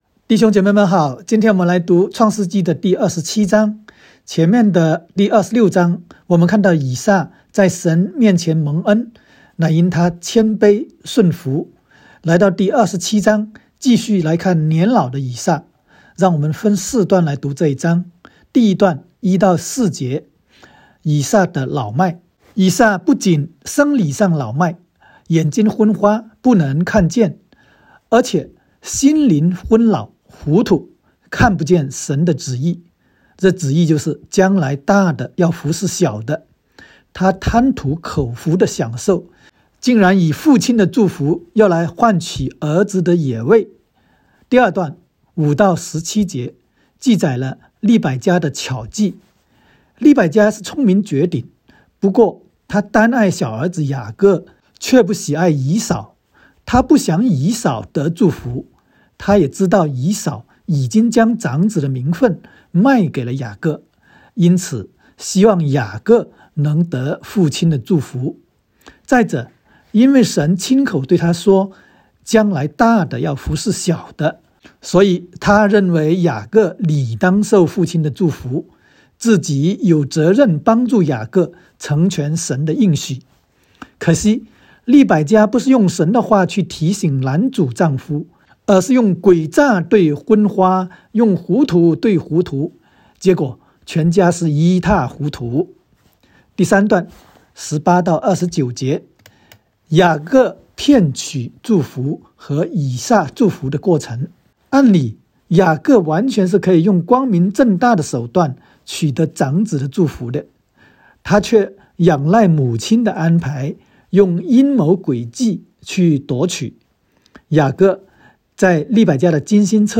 创27（讲解-国）.m4a